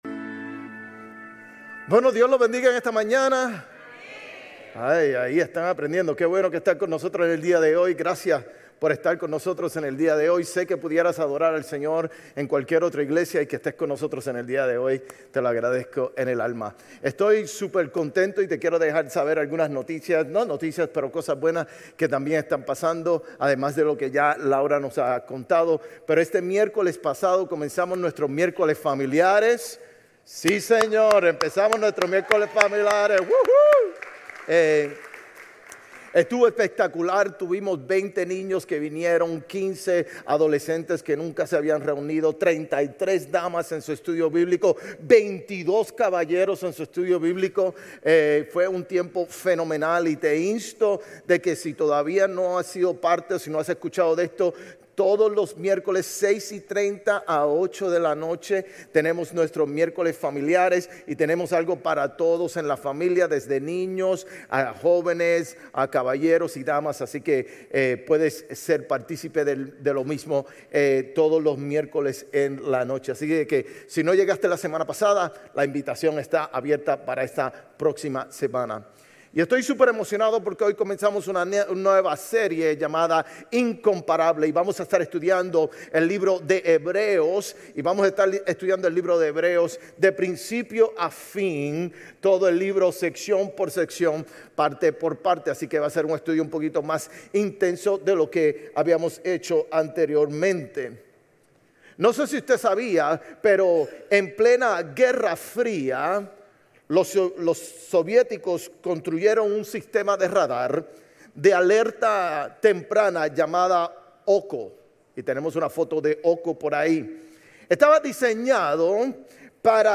Sermones Grace Español 9_14 Grace Espanol Campus Sep 15 2025 | 00:40:49 1x 00:00 / 00:40:49 Subscribe Share RSS Feed Share Link Embed